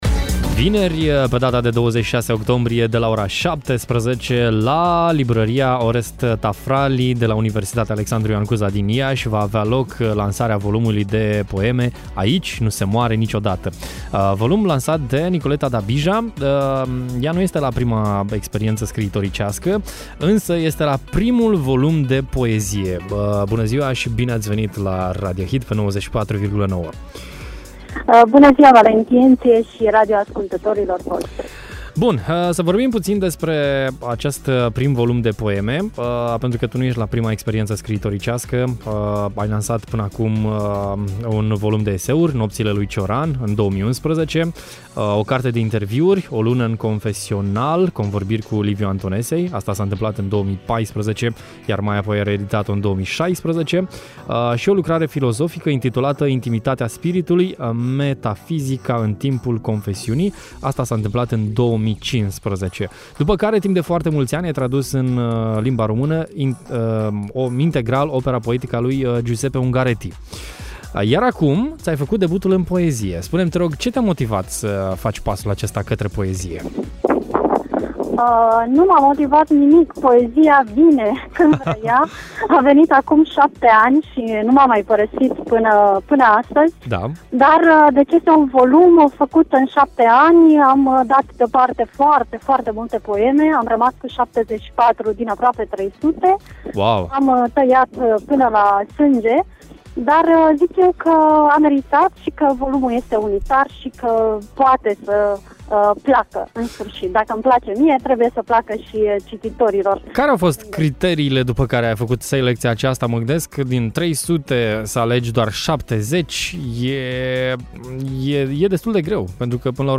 în direct la Radio Hit: